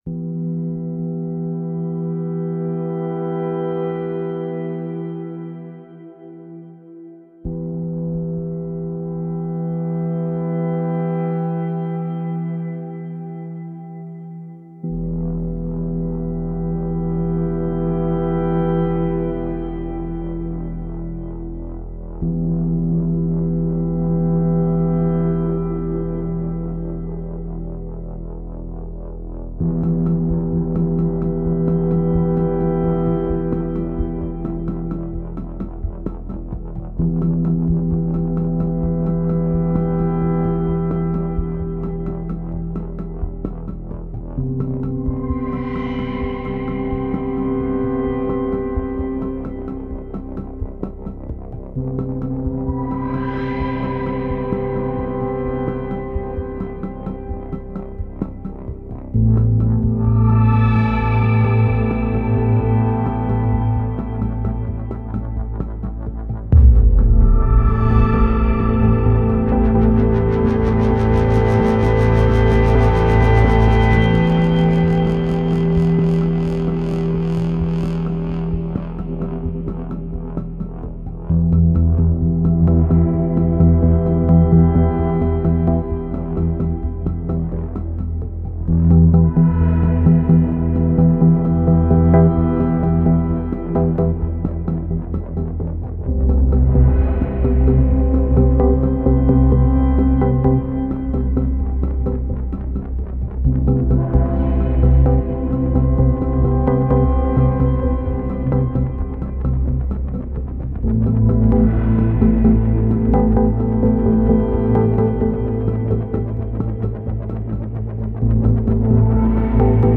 Otherworldly sounds refract and oscillate.